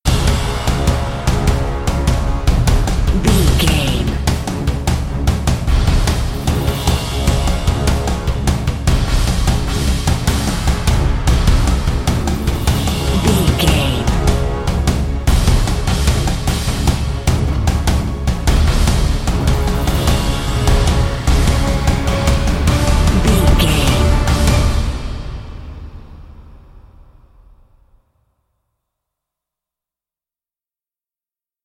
Fast paced
In-crescendo
Aeolian/Minor
percussion
orchestral hybrid
dubstep
aggressive
energetic
intense
strings
drums
bass
synth effects
wobbles
driving drum beat
epic